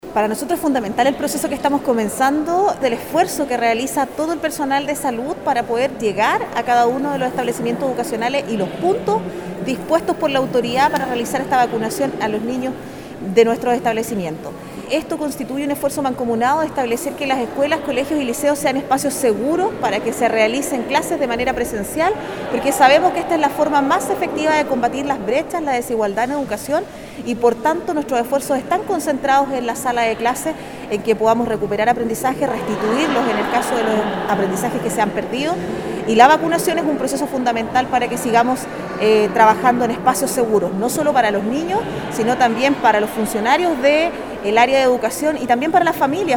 También subrayó la importancia de esta etapa de la lucha contra el Coronavirus, la seremi de Educación de Los Lagos, Paulina Lobos, quien expresó que este tipo de prevenciones en materia de vacunación permite mayor seguridad a las comunidades educativas y a las familias.